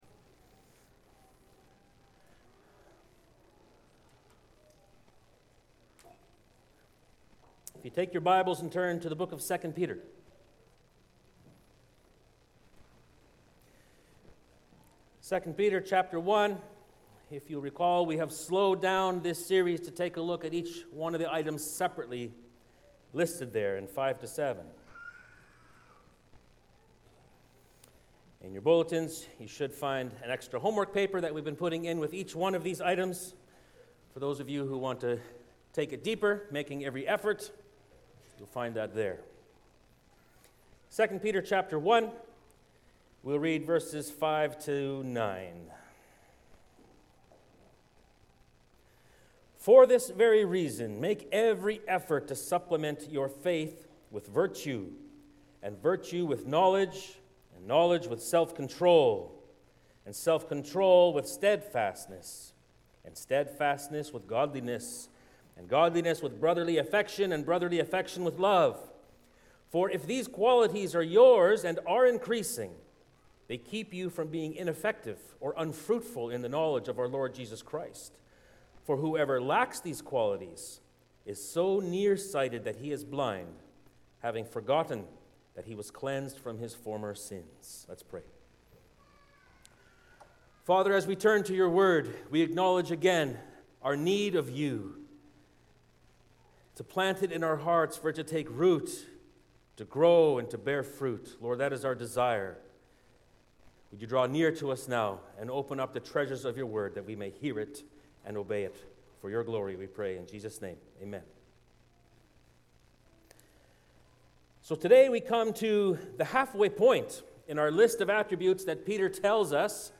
Sermons | Cornerstone Bible Church